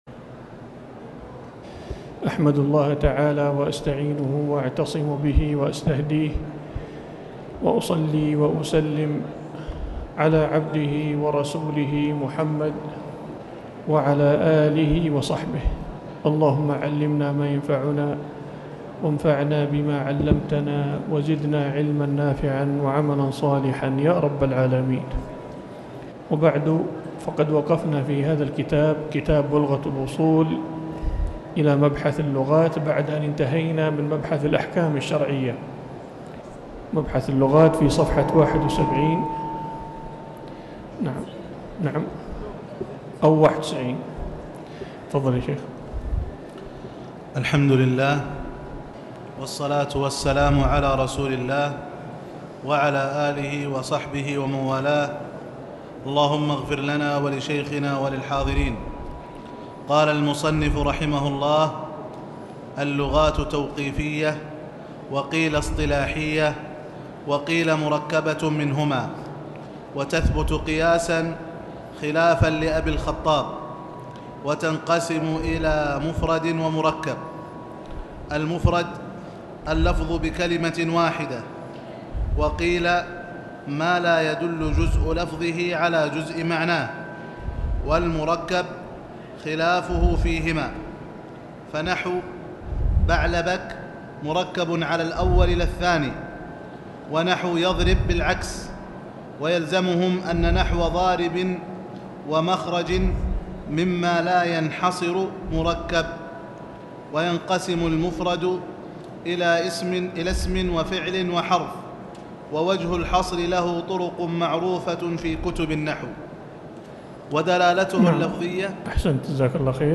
تاريخ النشر ٨ ذو القعدة ١٤٤٠ المكان: المسجد الحرام الشيخ